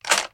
hkReload.ogg